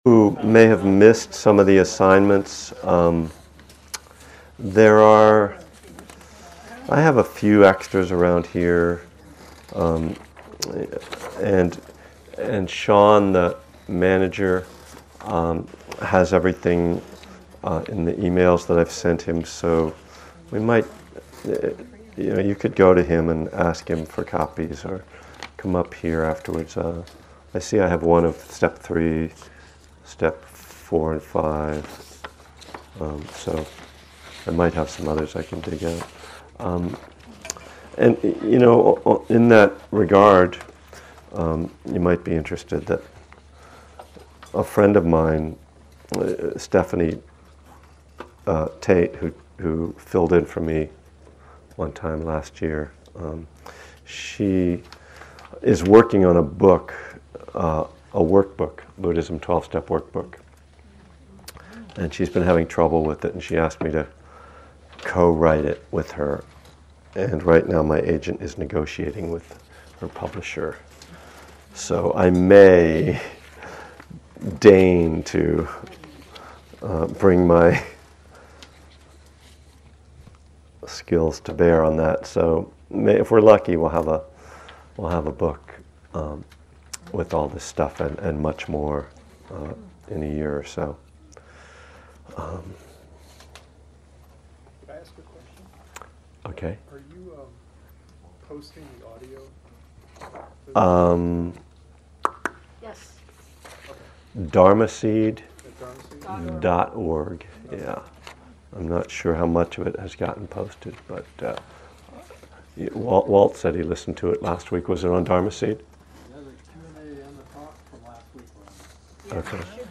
From the Spirit Rock Buddhism and the Twelve Steps class, March of 2011.